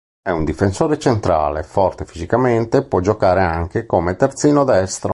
Pronúnciase como (IPA) /fi.zi.kaˈmen.te/